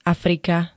Zvukové nahrávky niektorých slov
5uxf-afrika.ogg